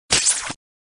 Звуки Человека-Паука, паутины